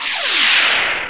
thrust.wav